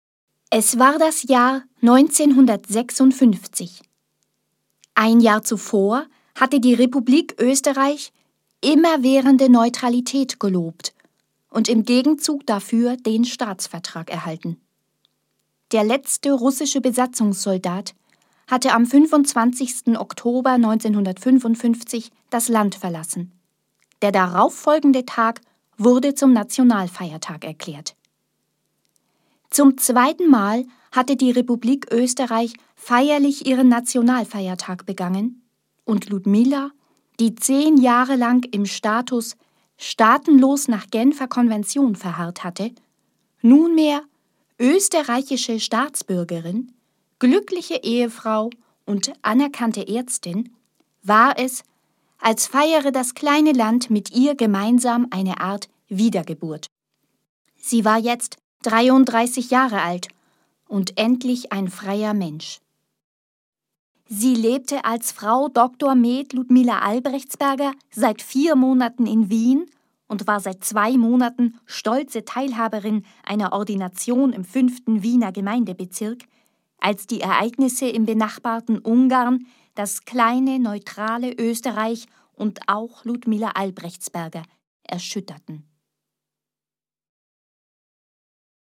Hier sind einige Beispiele, wie es klingt, wenn ich aus meinen Texten etwas vorlese.